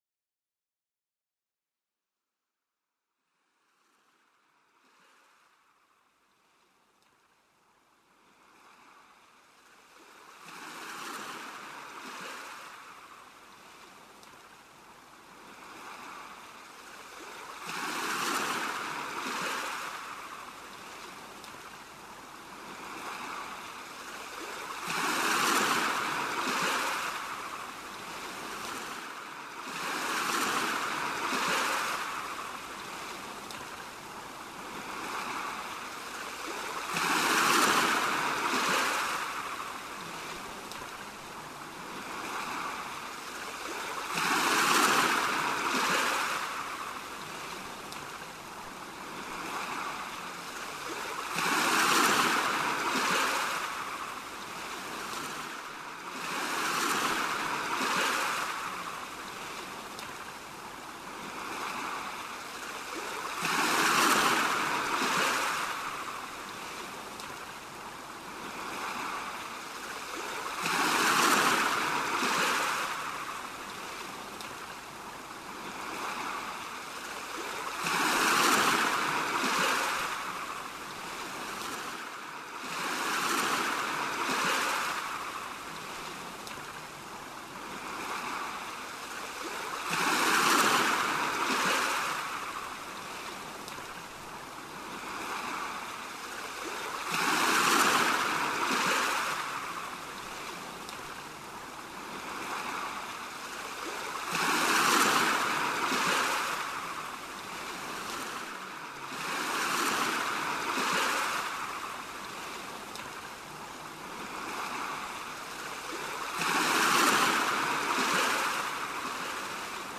הרפיה והתרגעות, גלי ים עם סאבלימינלים